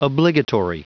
Prononciation du mot obligatory en anglais (fichier audio)
Prononciation du mot : obligatory